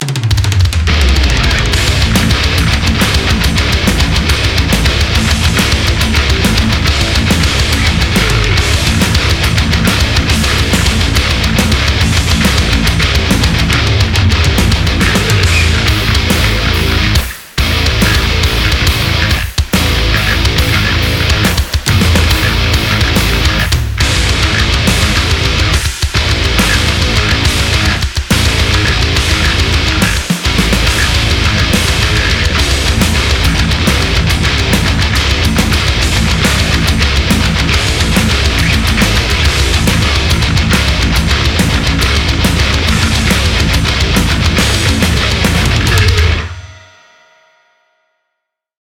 Твоя баробанная вавка не подошла ... использовал тваю мидюху и свои баробаны ... Басяра на диайнике безбожно клипит ... так что подгрузил его чтоб хрюкал дастойно ....